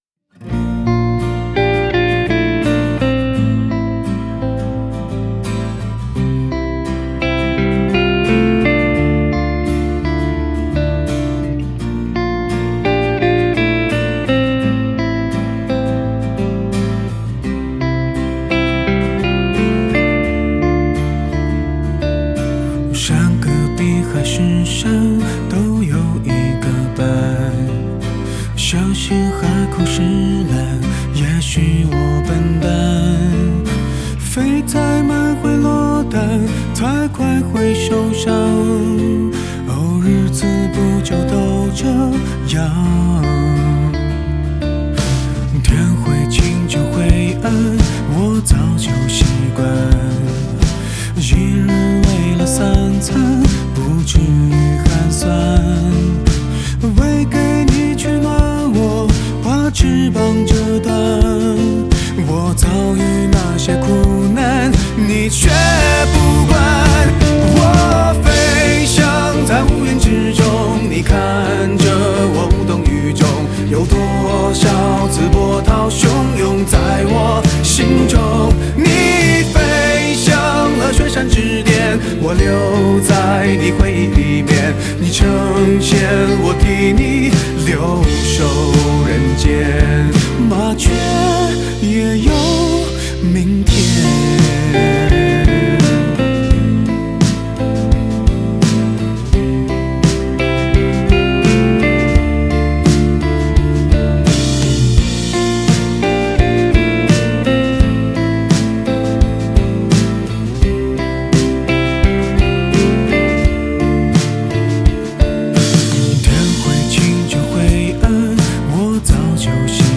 Ps：在线试听为压缩音质节选，体验无损音质请下载完整版 词